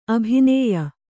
♪ abhinēya